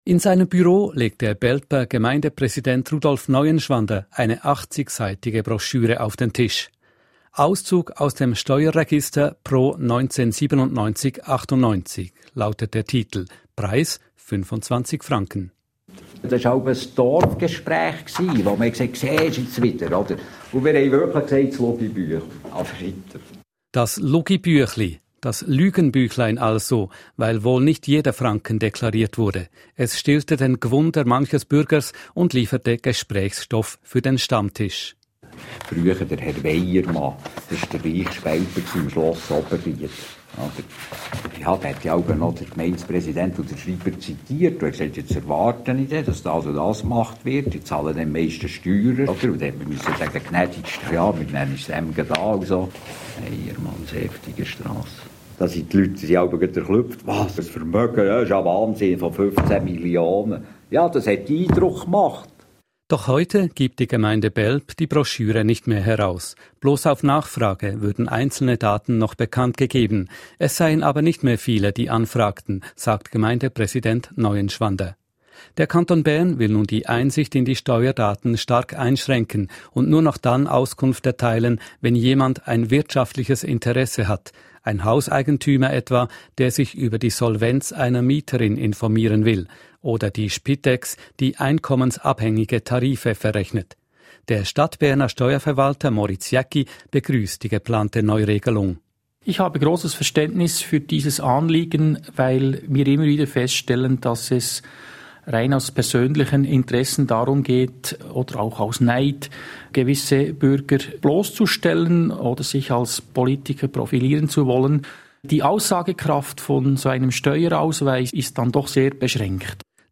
Dass die Interview-Antworten so locker, offen und bärndütsch ertönen, hat seinen Grund: Es sind die Aussagen «after record», im lockeren Nachgespräch, wo der Reporter sein Mikrofon aber auf «on» liess. Man hört darin also die interessanten Sachen, es raschelt in den Seiten des Registers («ds Lugibüechli»), der Ton ist echt statt perfekt.